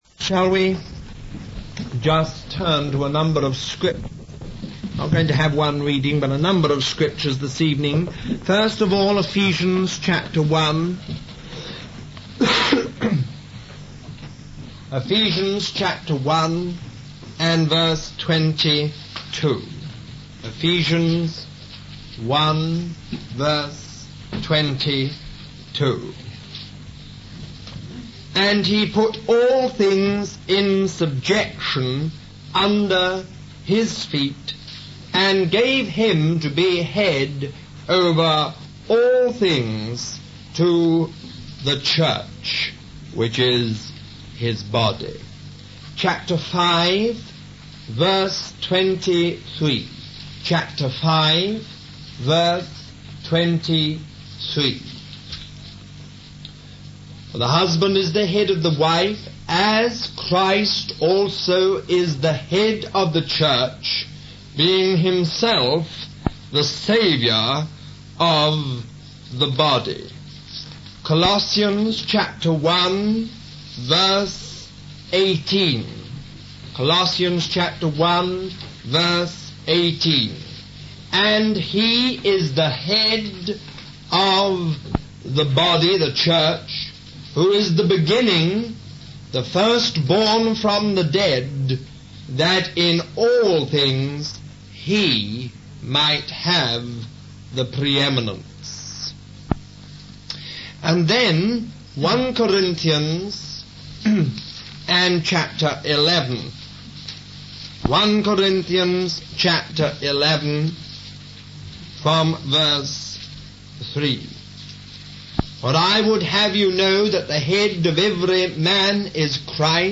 A collection of Christ focused messages published by the Christian Testimony Ministry in Richmond, VA.
Halford House, England